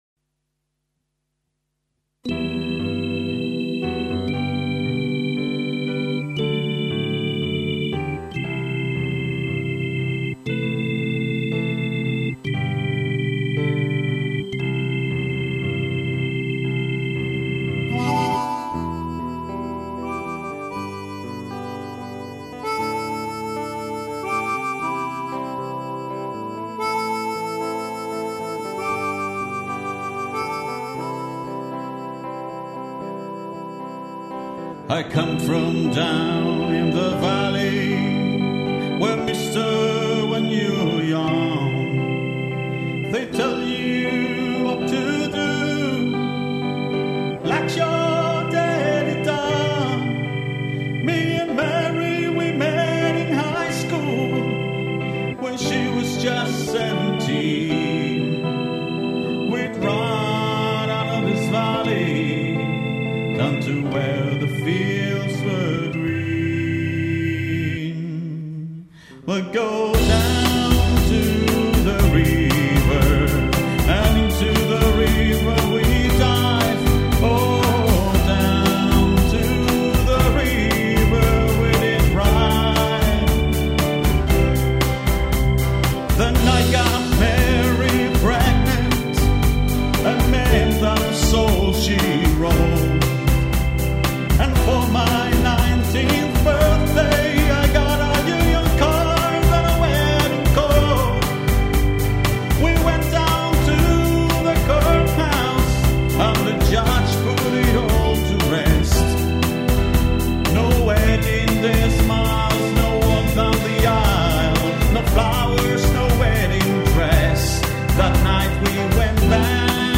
Die Musikschulband (2020 - 2021)